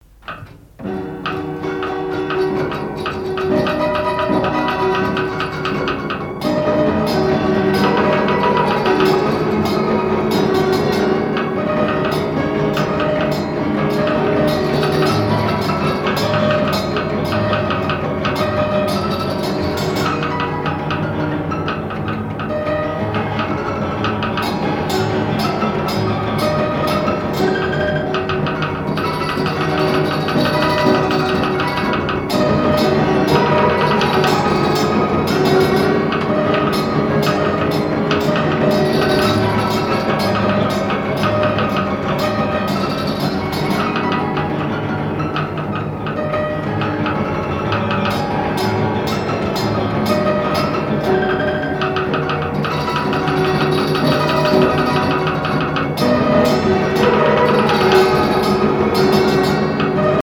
piano mécanique
danse : marche
Pièce musicale inédite